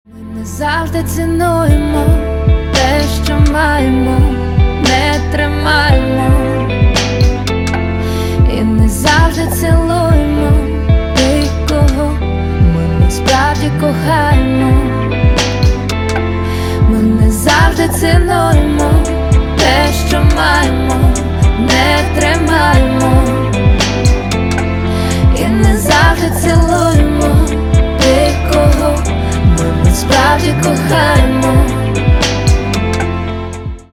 грустные # спокойные